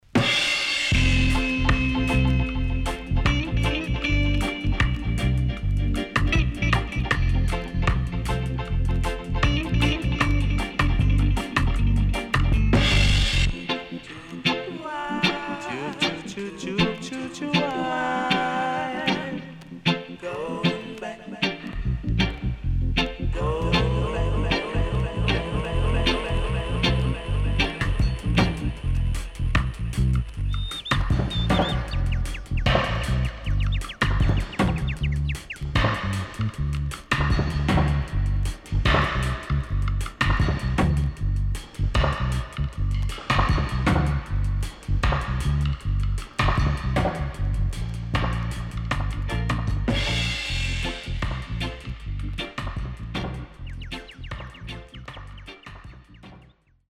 SIDE A:所々チリノイズ入ります。